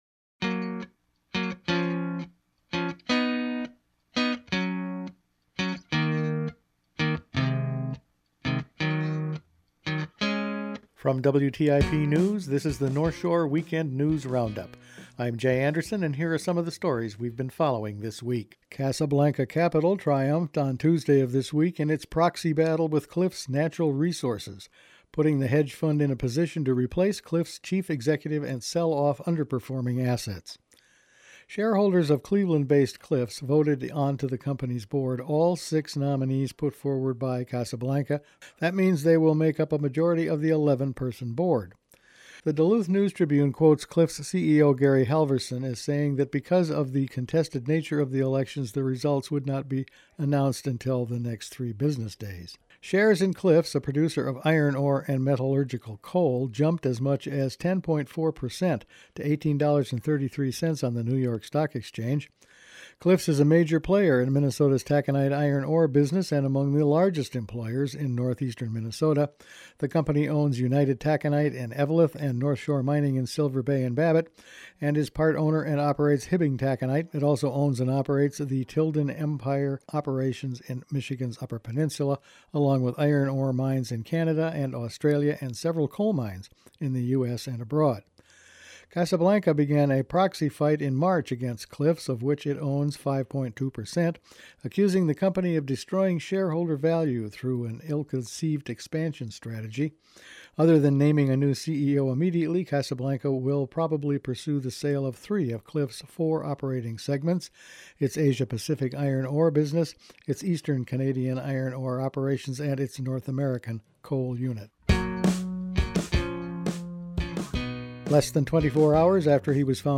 Each week the WTIP news department puts together a roundup of the weeks top news stories. A take over at Cliffs Natural Resources and developments in the county attorney sexual misconduct case…all this and more in this week’s news.